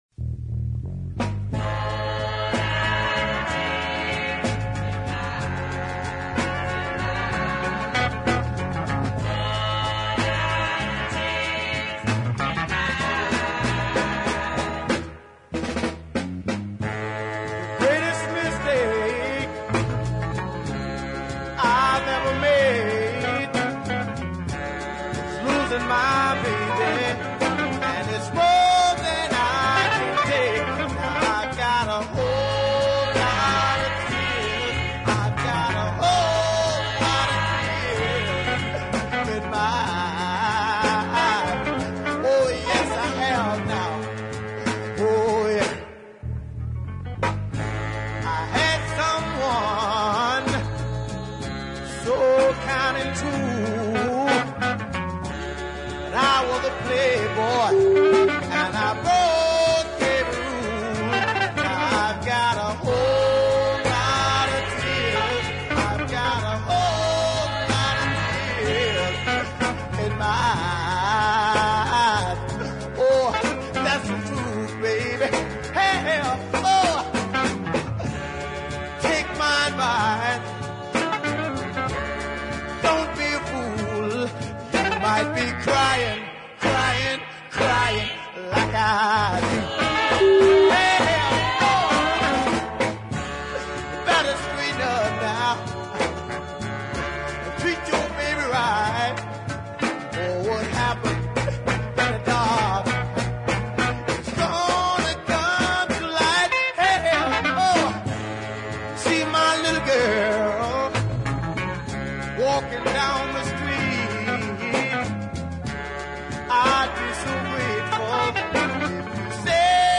is a celebrated deep soul classic